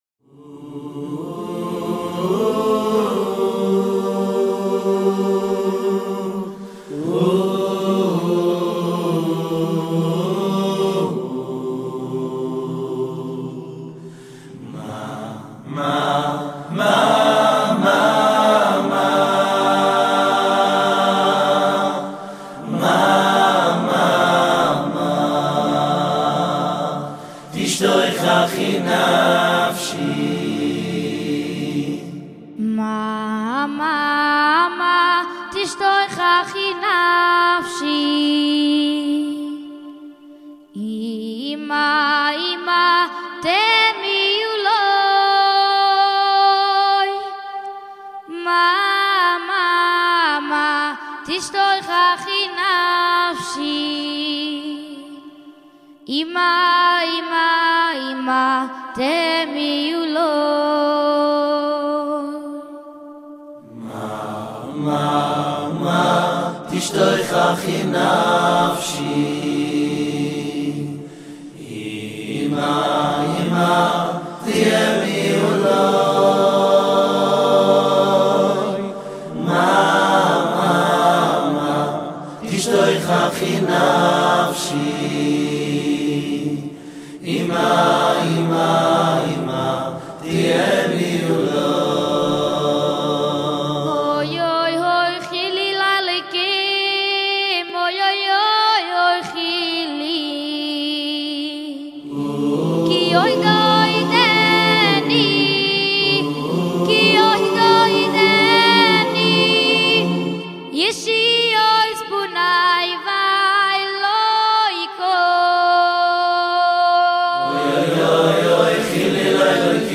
גרסת האקפלה